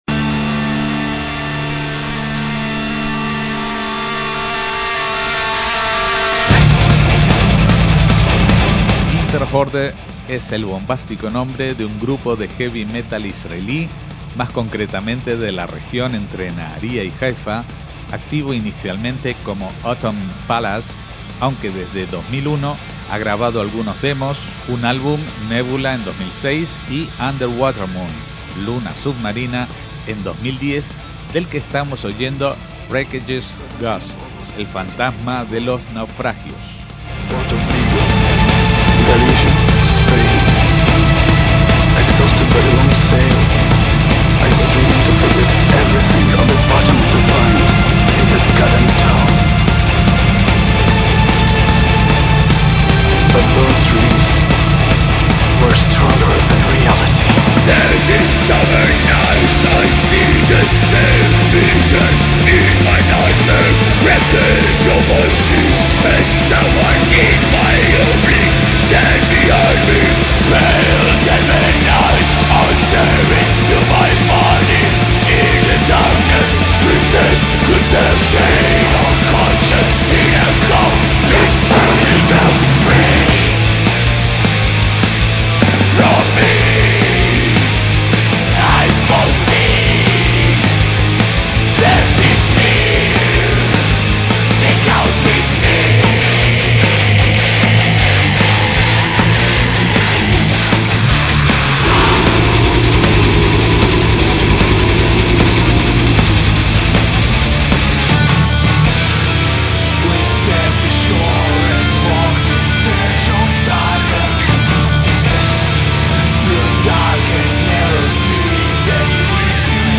PONLE NOTAS - Esta banda de heavy metal israelí se formó en 2001 en la Galilea.